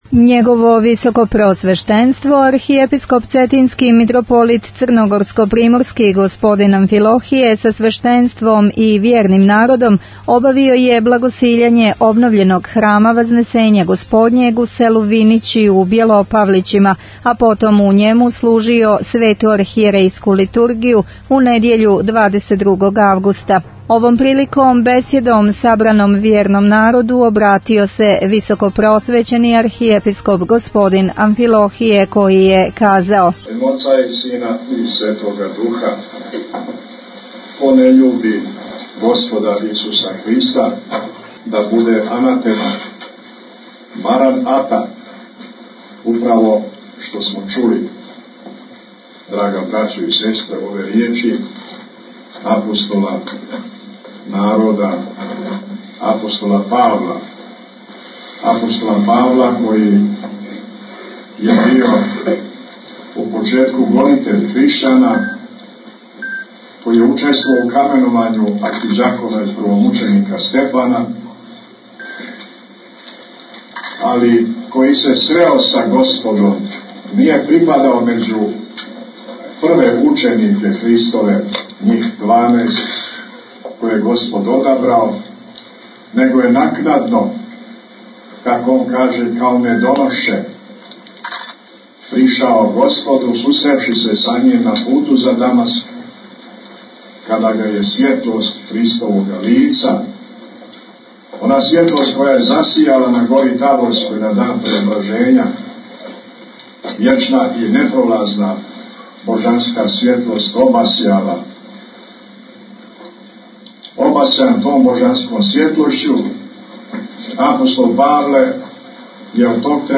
Tagged: Бесједе